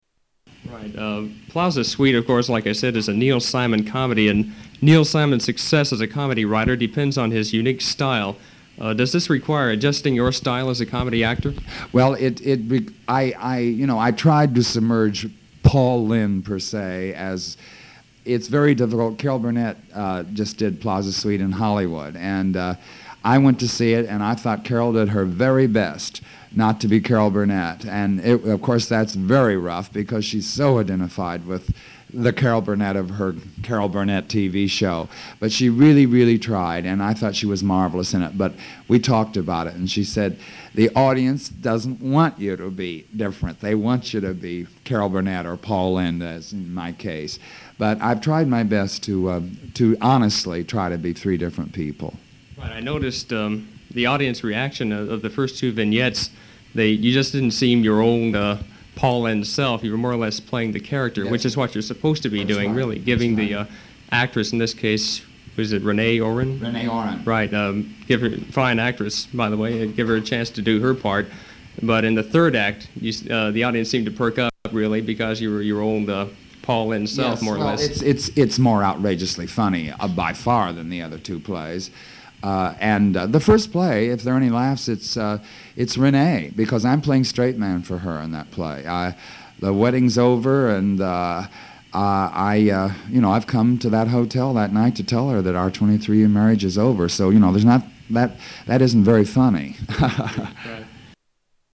Interview July, 1971 part 2
Category: Comedians   Right: Personal